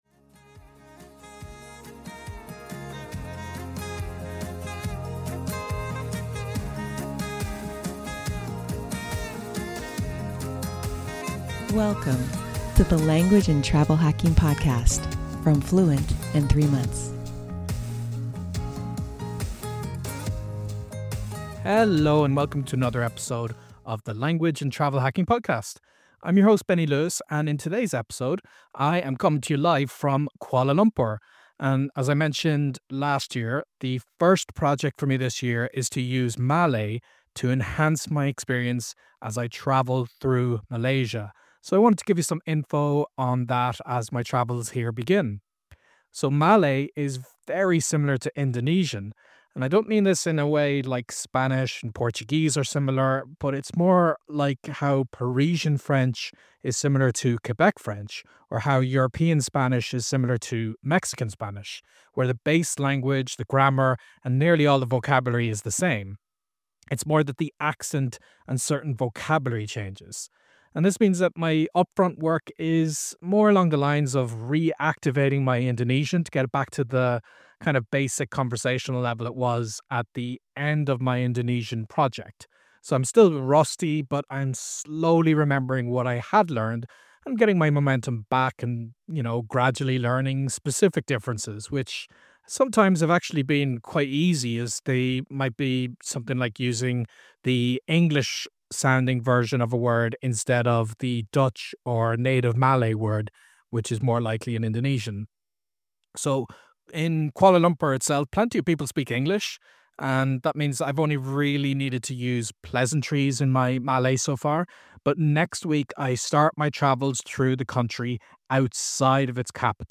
In this episode, I’m checking in from Kuala Lumpur to kick off my first language and travel project of the year: using Malay to enrich my experience as I travel through Malaysia. I talk about how closely related Malay and Indonesian are, more like regional varieties than entirely separate languages, which means I can focus on reactivating my Indonesian rather than starting from zero.